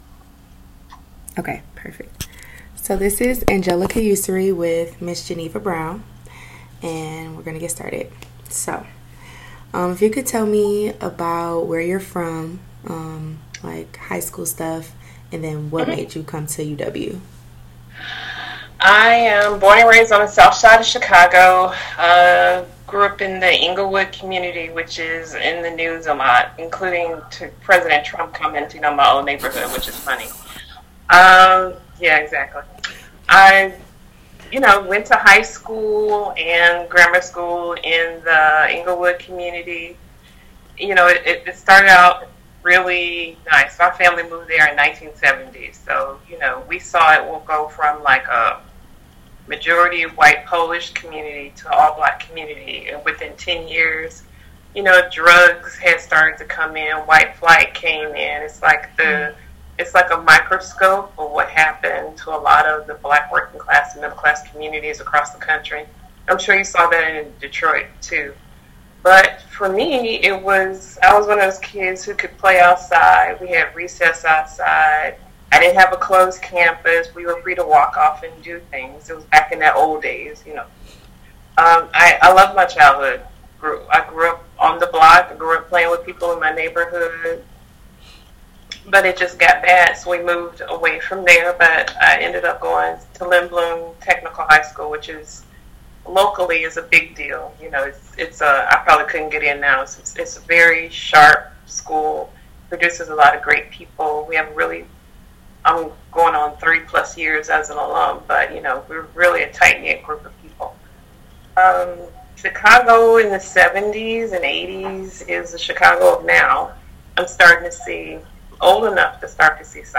University of Wisconsin-Madison Oral History Program